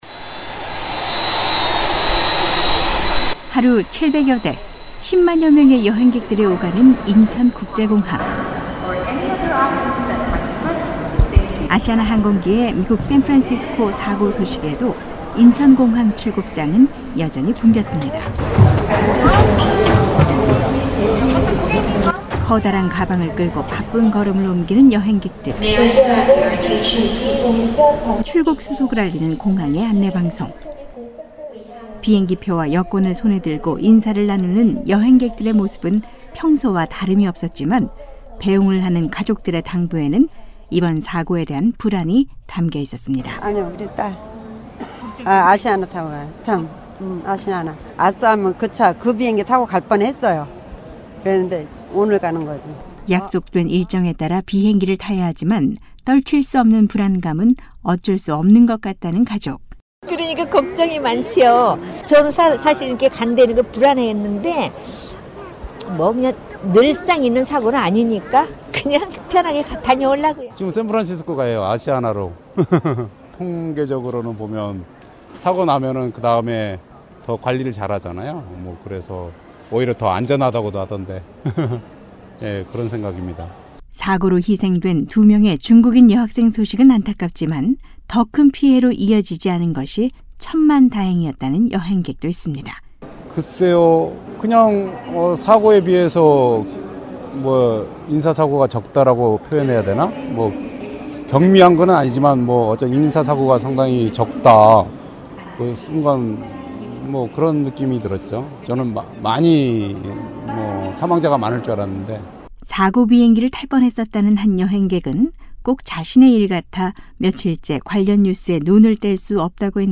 한국사회의 이모저모를 알아보는 ‘안녕하세요. 서울입니다’ 오늘은 지난 7일(한국시각) 미국 샌프란시스코 공항에서 일어난 아시아나 항공기 추락사고에 대한 한국 시민들의 목소리를 들어보겠습니다.